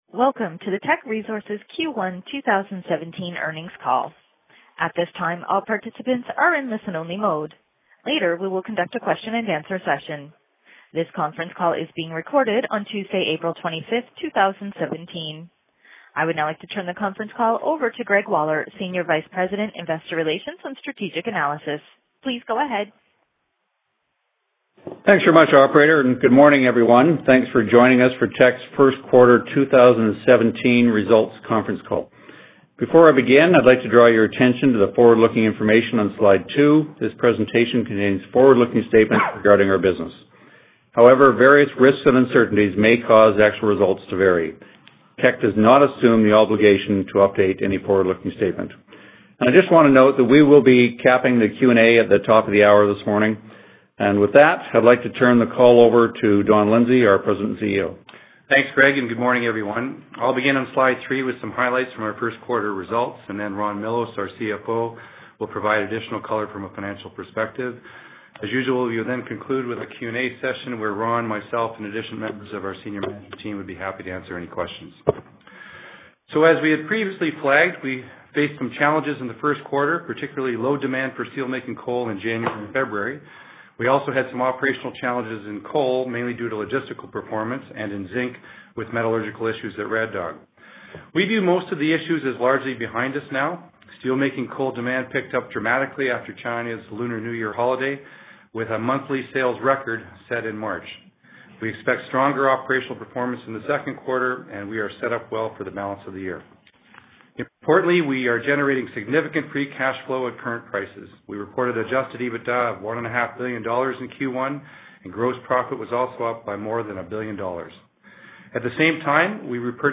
Q1 2017 Financial Report [PDF - 1.92 MB] Q1 2017 Financial Report Presentation Slides [PDF - 1.26 MB] Q1 2017 Financial Report Conference Call Audio [MP3 - 5.55 MB] Q1 2017 Financial Report Conference Call Transcript [PDF - 0.19 MB]